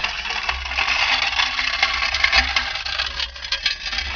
metalLightOnStone_lp.WAV